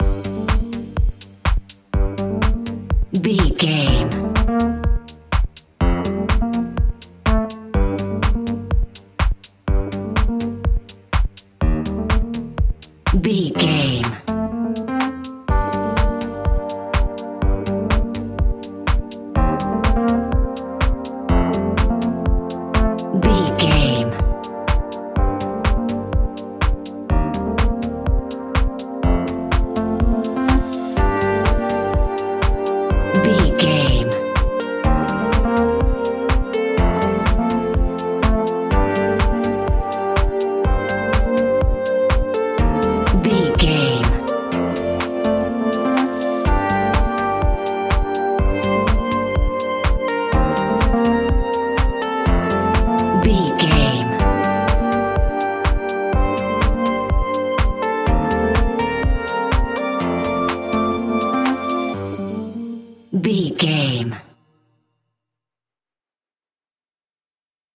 Aeolian/Minor
groovy
synthesiser
drum machine
electric piano
funky house
deep house
nu disco
upbeat
funky guitar
synth bass